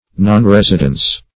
Search Result for " nonresidence" : The Collaborative International Dictionary of English v.0.48: Nonresidence \Non*res"i*dence\, n. The state or condition of being nonresident, --Swift.